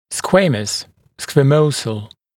[ˈskweɪməs] [skwəˈməusl][ˈскуэймэс] [скуэˈмоусл]сквамозный, покрытый чешуйками